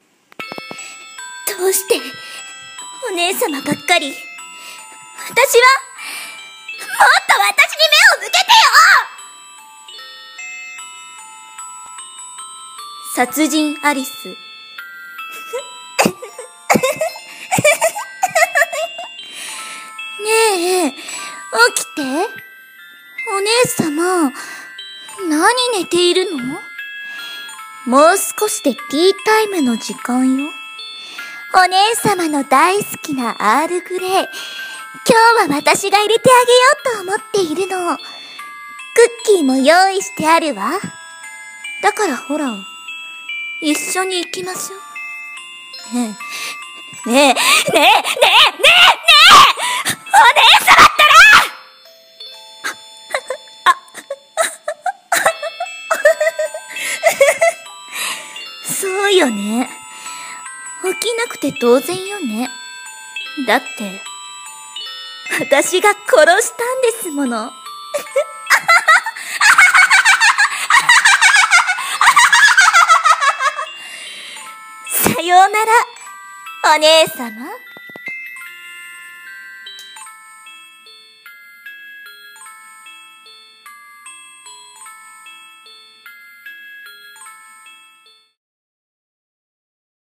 朗読声劇台本